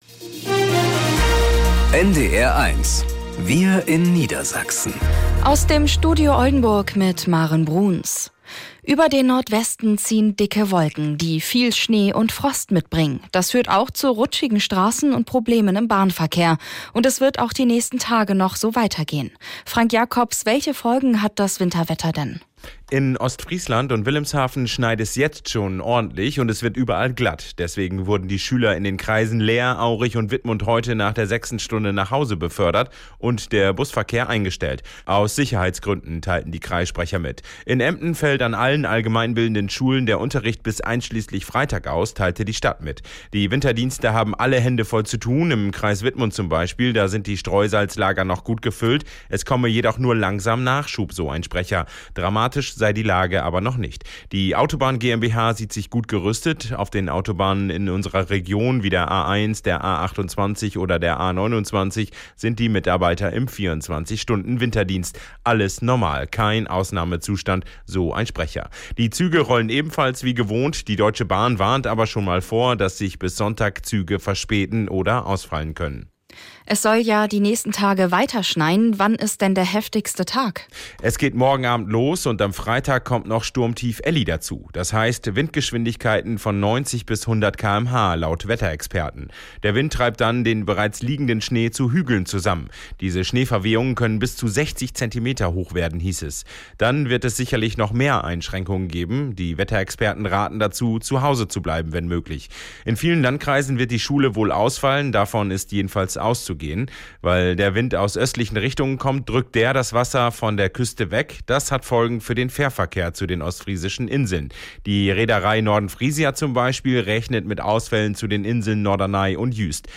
~ Wir in Niedersachsen - aus dem Studio Oldenburg | Nachrichten Podcast